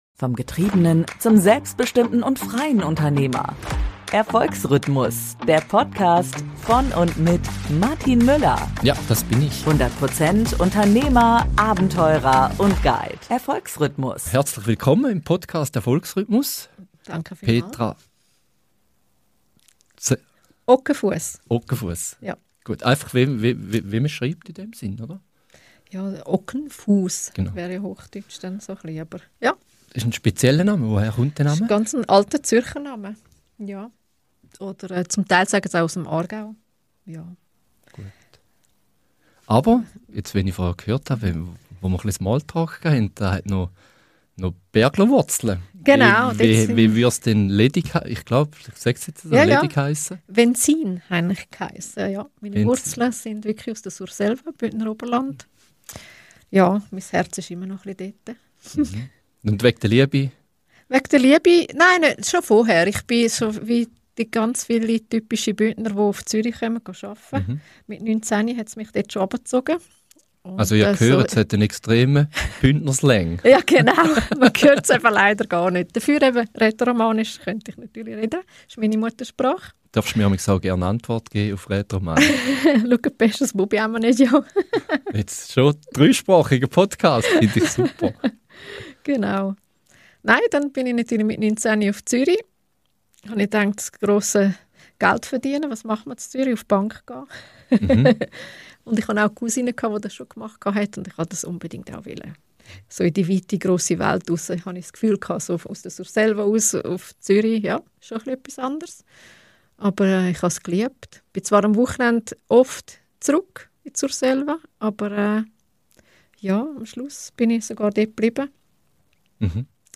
#42 Warum Scrollen keine Pause ist: Gedächtnistrainerin über digitale Überlastung, KI & Social Media ~ Erfolgsrhythmus Podcast